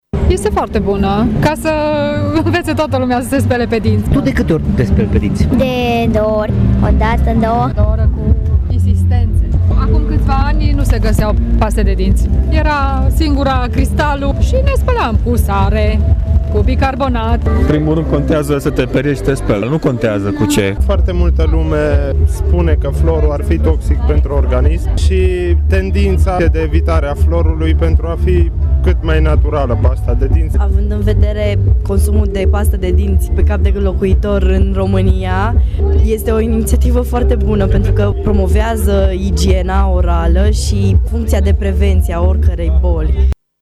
Participanții la evenimentul de la Tg.Mureș spun că igiena orală este fundamentală pentru sănătatea întregului organism și că prevenția este mama vindecării: